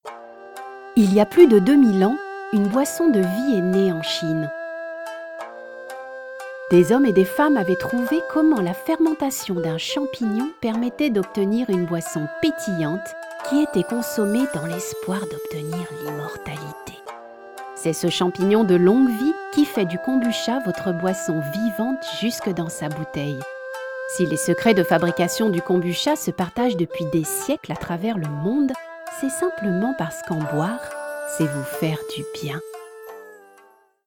Young, warm and smooth. French native speaker, german and English spoken
Sprechprobe: Werbung (Muttersprache):
My voice is a young female voice, that is friendly and warm.